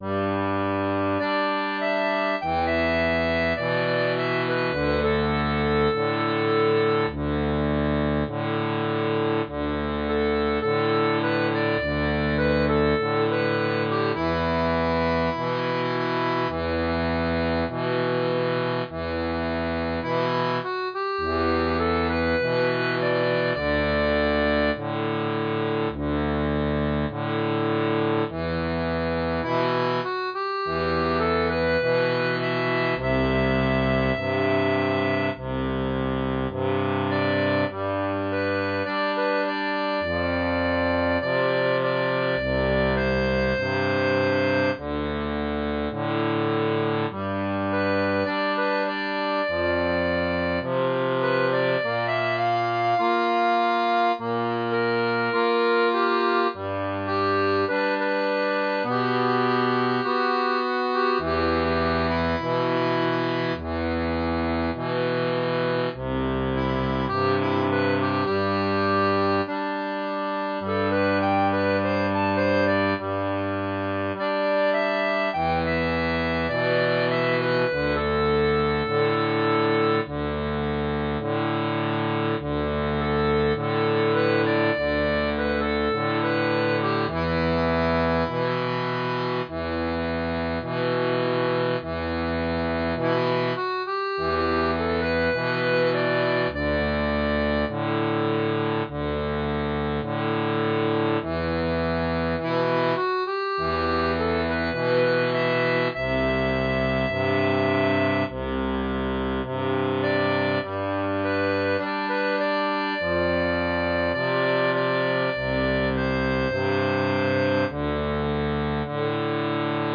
• une version pour accordéon diatonique à 3 rangs
Pop-Rock